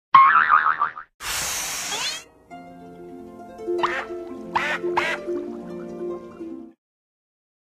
bubbleblow.ogg